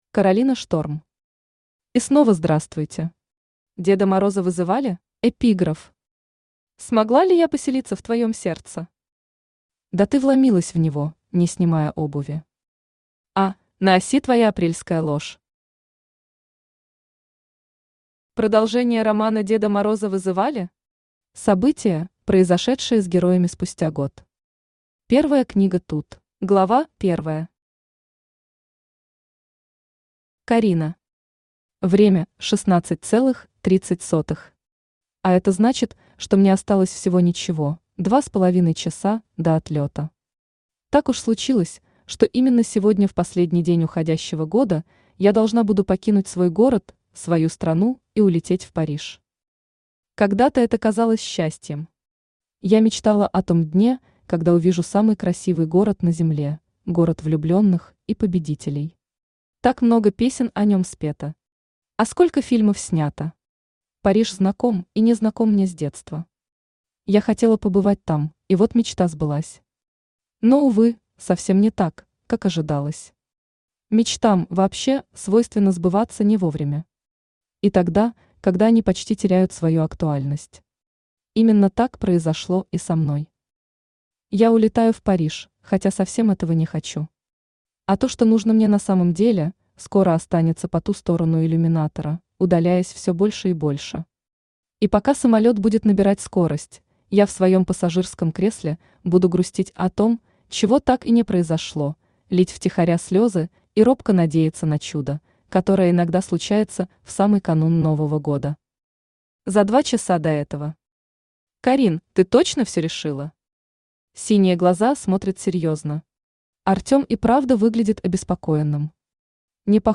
Аудиокнига И снова здравствуйте! Деда Мороза вызывали?
Автор Каролина Шторм Читает аудиокнигу Авточтец ЛитРес.